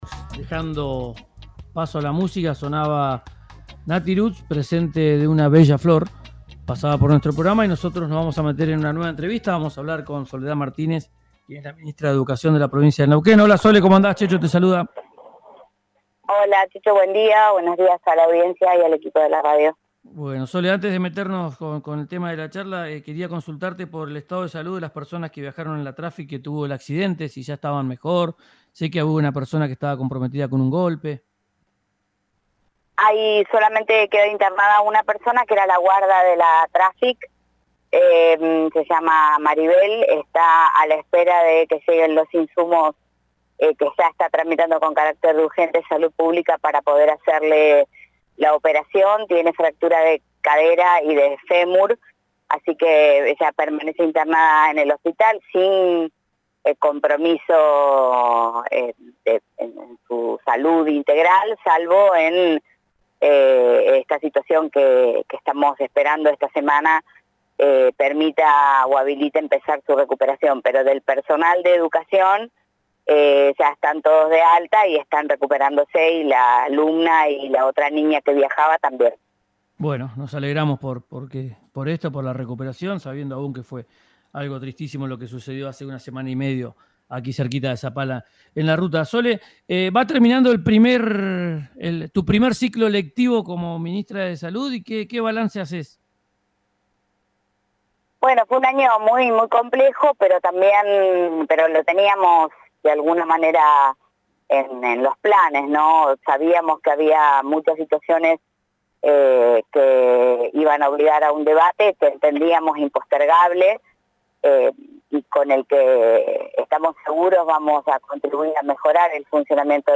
Escuchá a Soledad Martínez, ministra de Educación, en RÍO NEGRO RADIO: